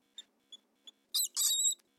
Жирнохвостая песчанка издает особый звук при дискомфорте